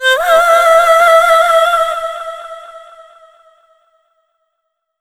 SCREAM1   -R.wav